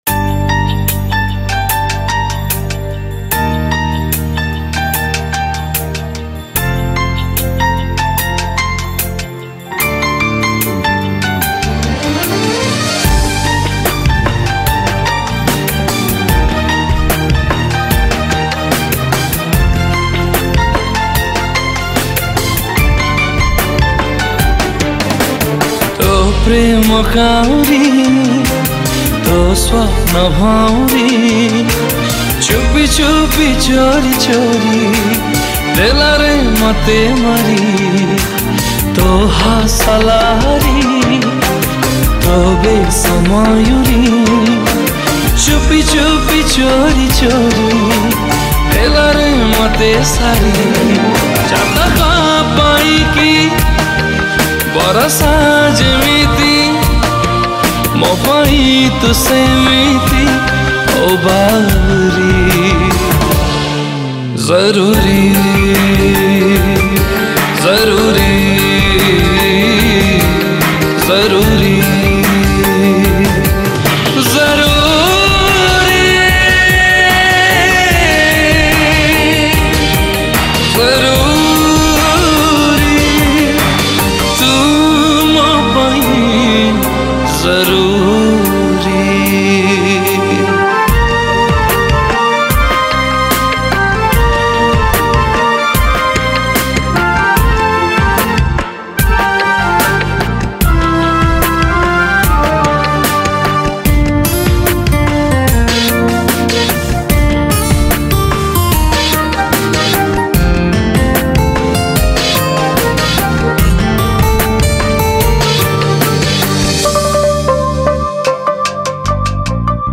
Romantic Odia Song